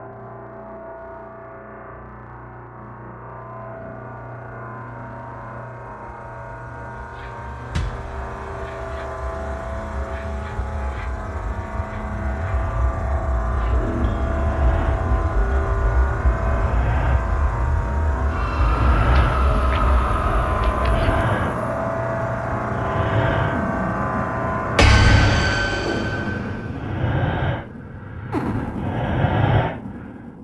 Horror Soundscape